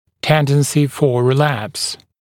[‘tendənsɪ fə rɪ’læps][‘тэндэнси фо ри’лэпс]тенденция к рецидиву